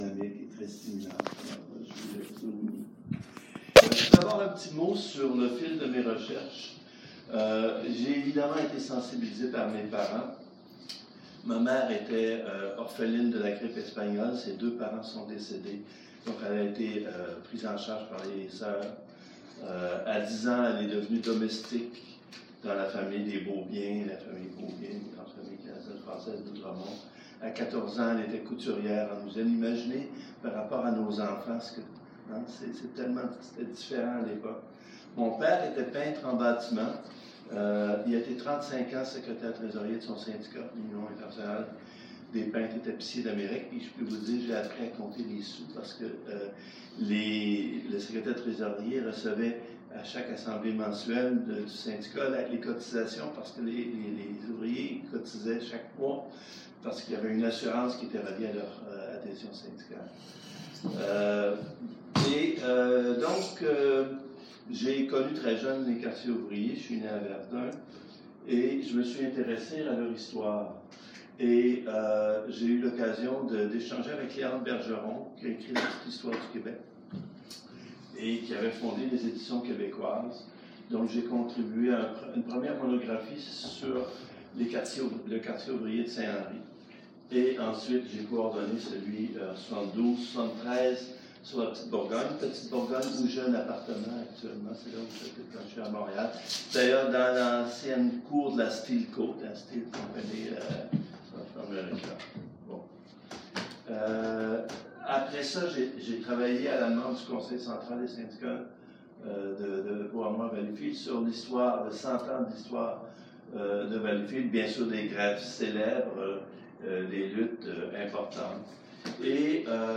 Enregistrement audio de la présentation et de la discussion qui a suivi :